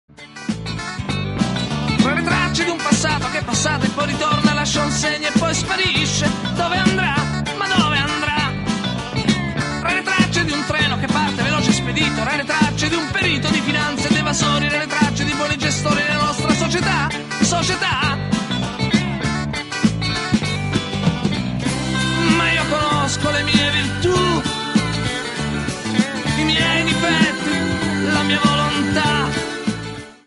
Sembra quasi rappato.